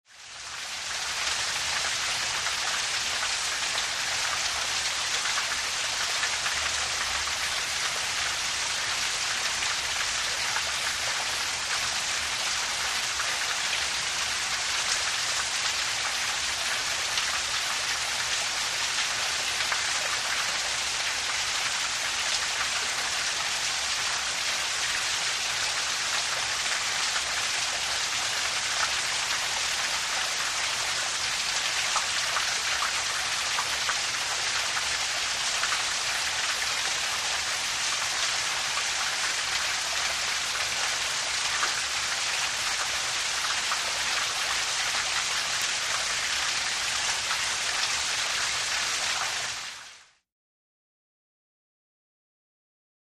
Water, Waterfall | Sneak On The Lot